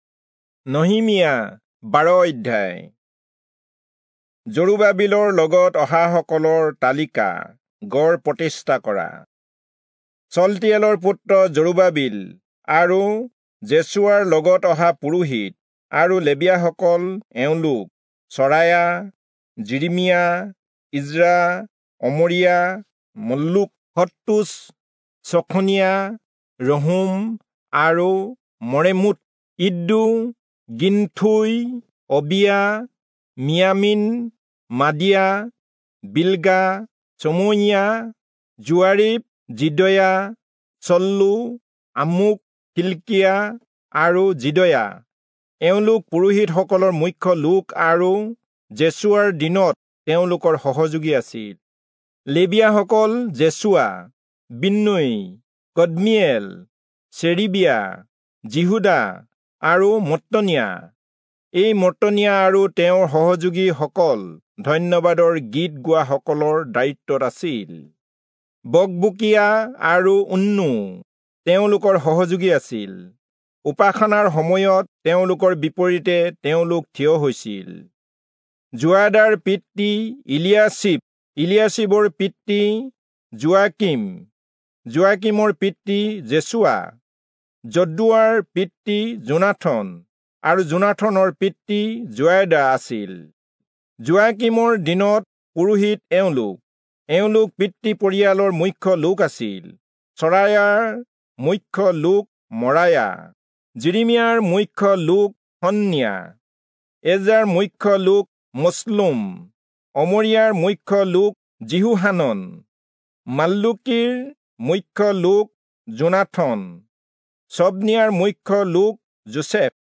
Assamese Audio Bible - Nehemiah 10 in Hov bible version